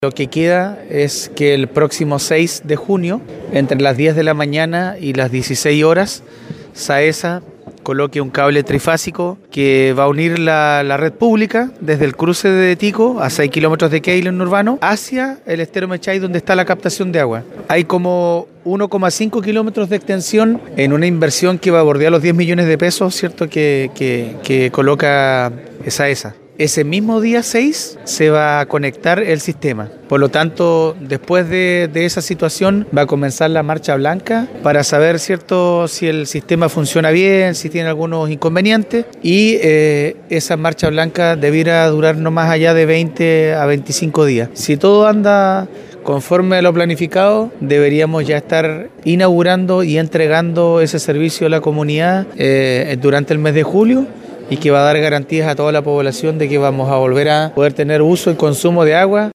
El edil de Quéilen precisó los detalles técnicos que faltarían por cubrir y que dotarían de energía eléctrica para que el sistema funcione.